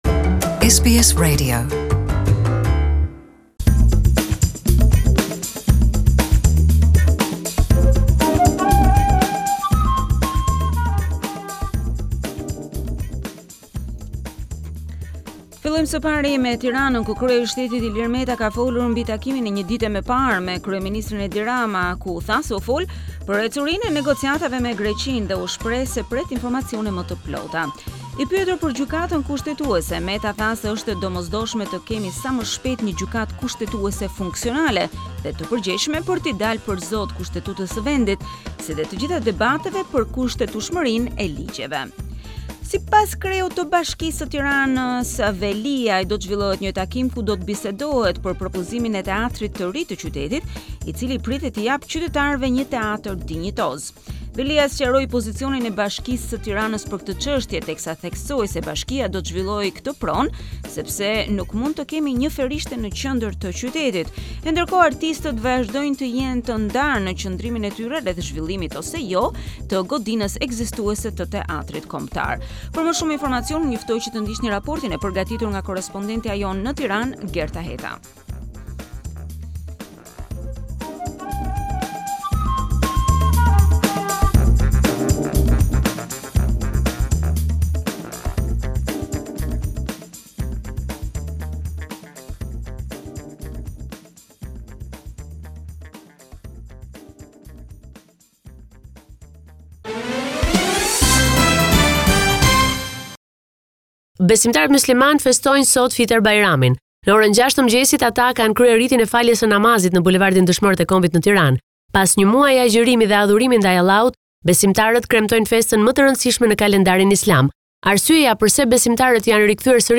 This report summarising the latest developments in news and current affairs in Albania.